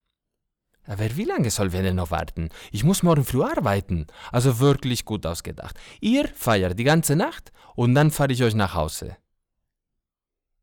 Jung (18-30)
Rundfunkbeitrag, journalistisch, Akzent
Comment (Kommentar), News (Nachrichten)
Spanish (Spain)